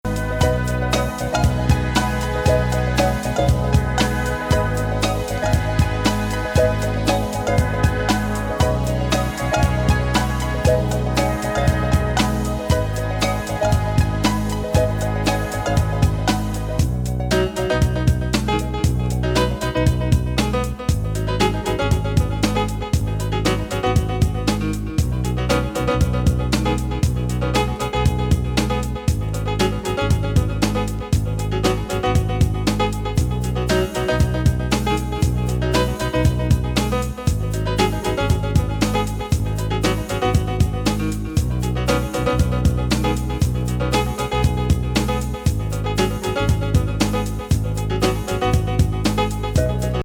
キーボードワークにオリエンタル風のストリングスが心地良過ぎな１枚。
ほど良いBPMで完成度抜群です！